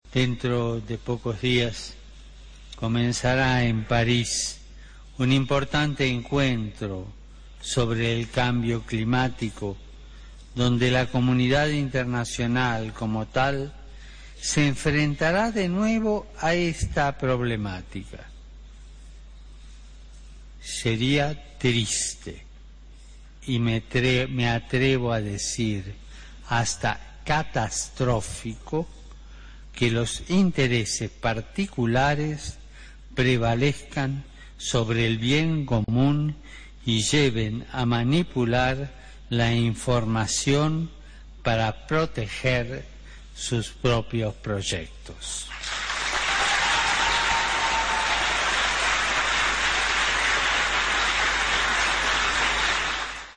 Escucha aquí el discurso del Papa en Naciones Unidas en Nairobi